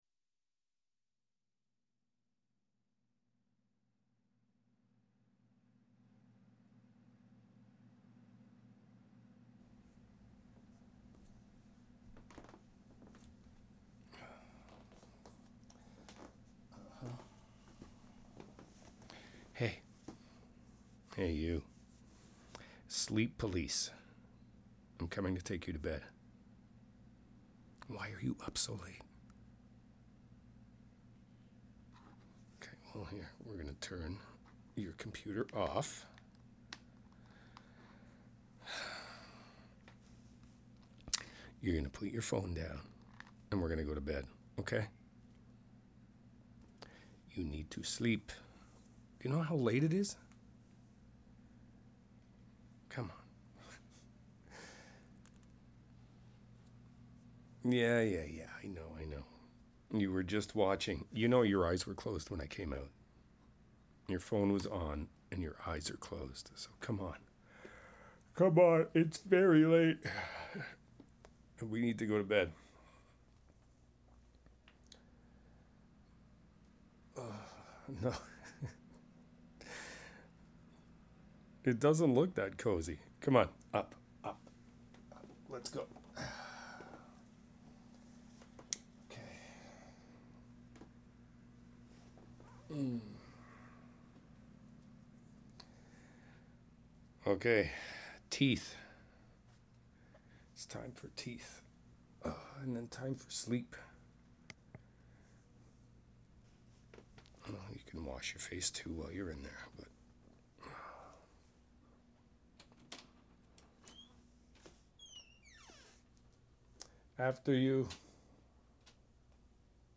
I love that one because it's so cute😄 I could listen to that part over and over again😏 And I love the sound of someone brushing their teeth😂 Maybe I have a weird sound fetish...